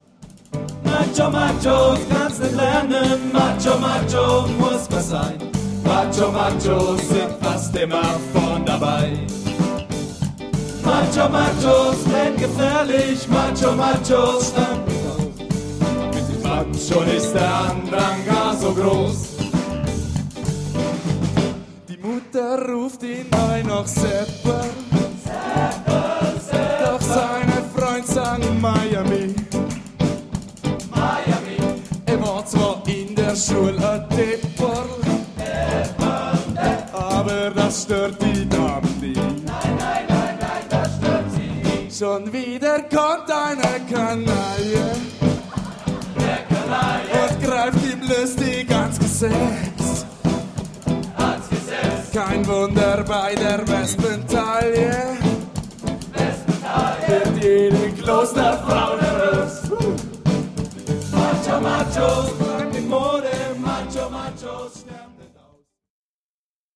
Band
TTB & Solo M